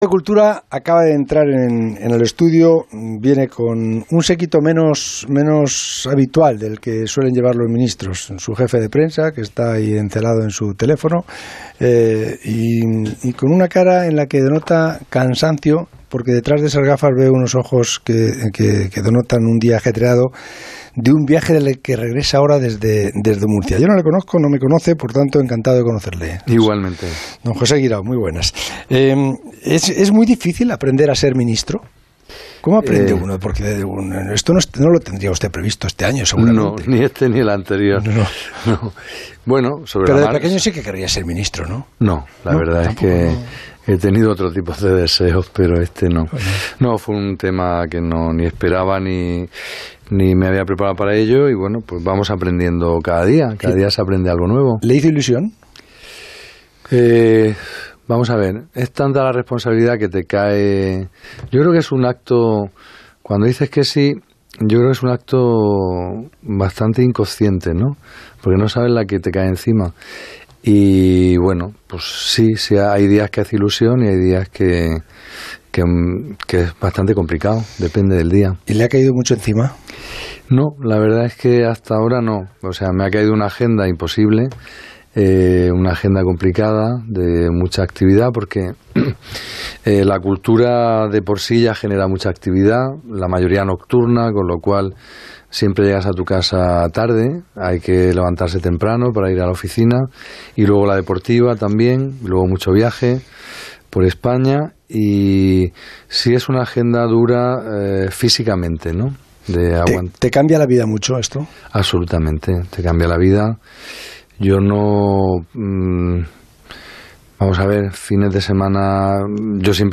El ministro de Cultura y Deporte, José Guirao, pasó en la noche del lunes por los micrófonos de El Transistor, de Onda Cero.